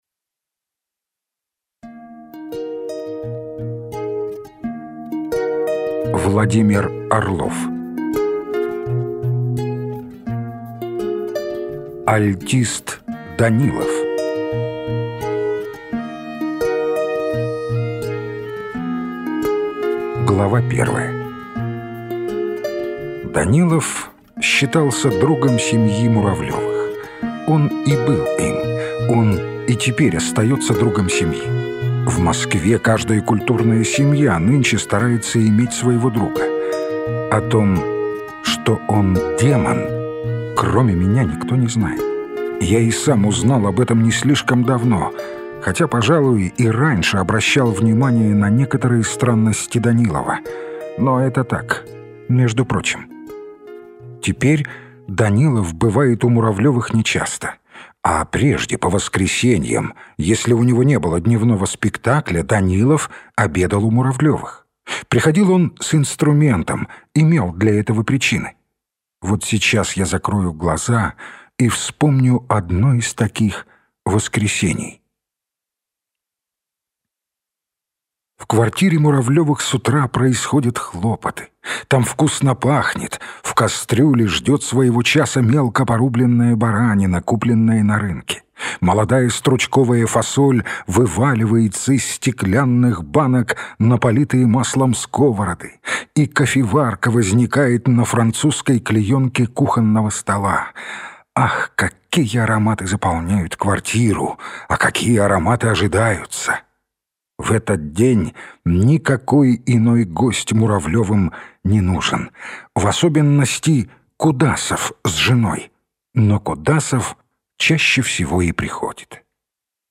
Аудиокнига Альтист Данилов - купить, скачать и слушать онлайн | КнигоПоиск